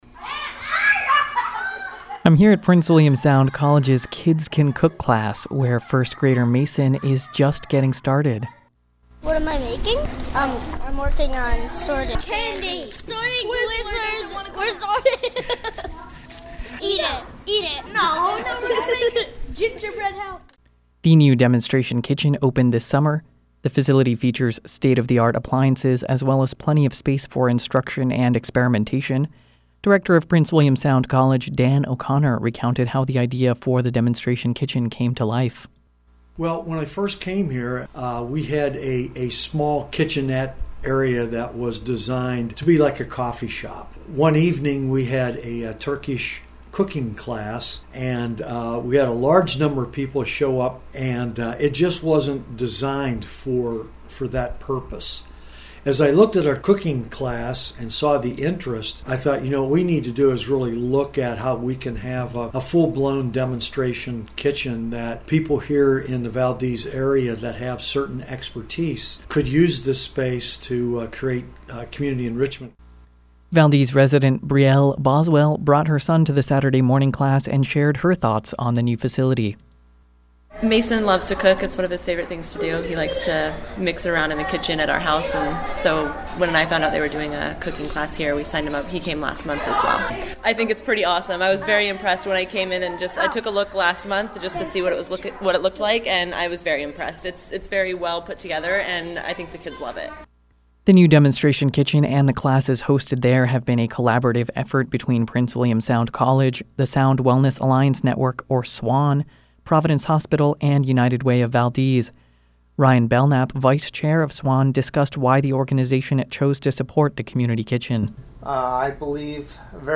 Arts & Entertainment, Education, Featured, KCHU Audio, Life, Uncategorized